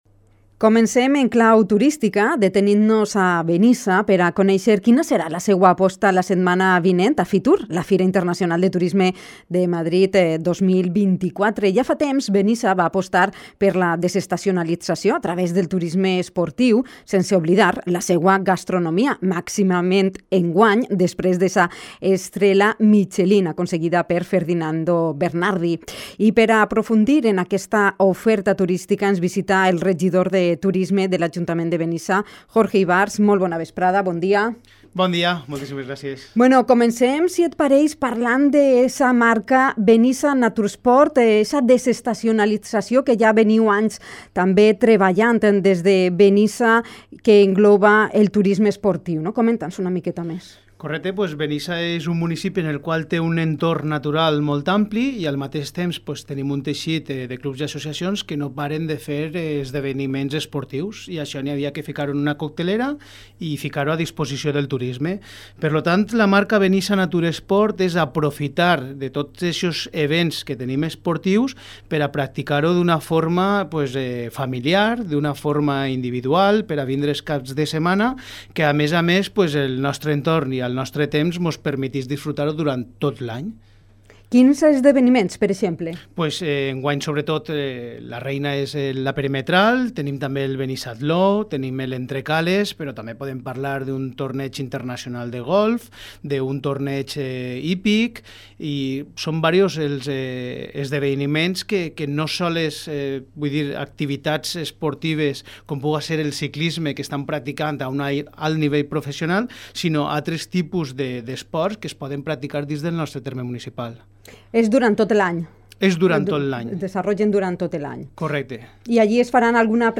Esta es una de las líneas de promoción turística señaladas por el concejal de Turismo del Ayuntamiento de Benissa, Jorge Ivars, en la entrevista mantenida, el jueves 18 de enero, en Dénia FM.
Entrevista-Jorge-Ivars-Fitur-Benissa.mp3